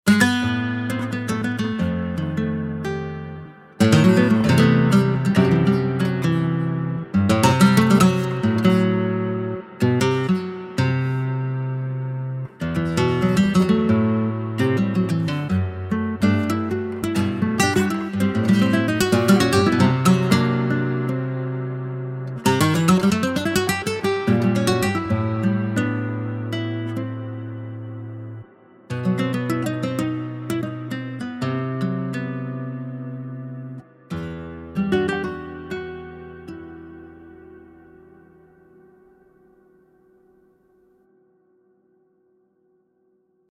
Химия, конечно тоже присутствует, но пару человек (вокалистов ) уже обманул, сказав , что это живаго.
Вложения Nylon intro.mp3 Nylon intro.mp3 1,7 MB · Просмотры: 401